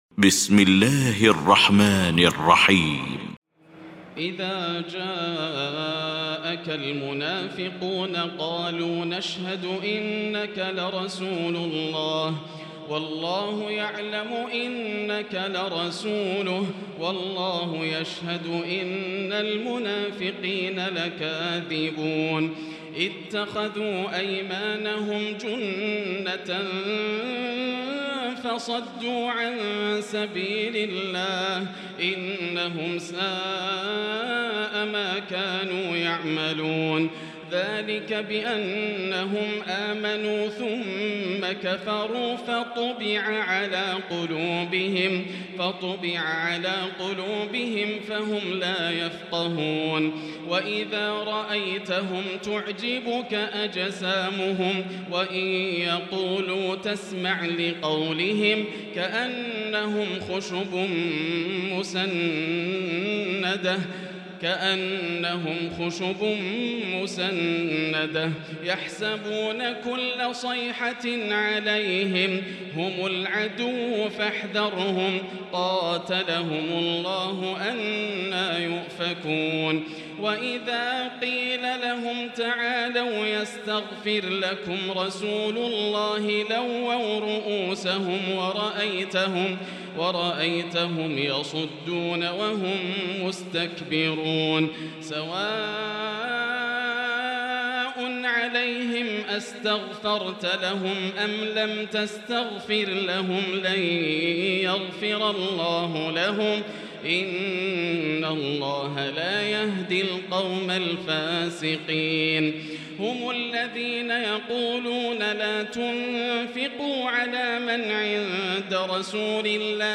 المكان: المسجد الحرام الشيخ: فضيلة الشيخ ياسر الدوسري فضيلة الشيخ ياسر الدوسري المنافقون